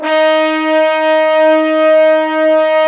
Index of /90_sSampleCDs/Roland LCDP06 Brass Sections/BRS_Fat Section/BRS_Fat Pop Sect